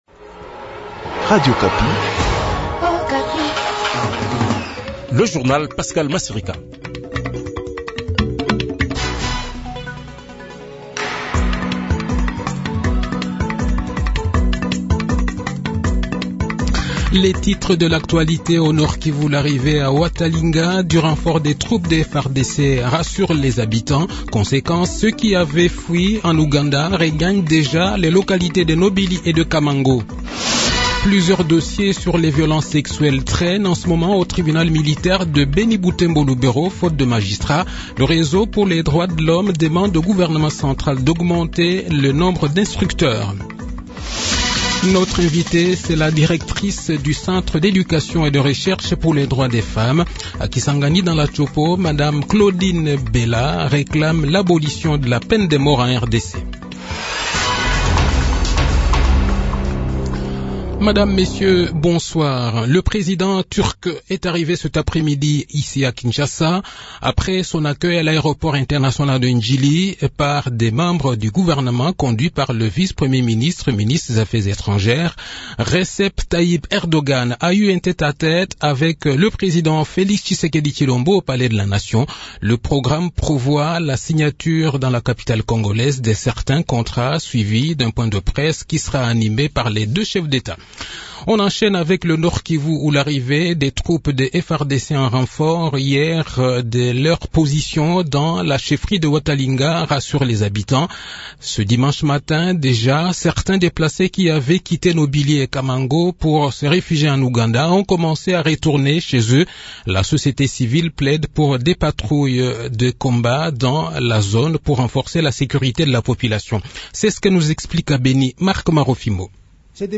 Le journal de 18 h, 20 février 2022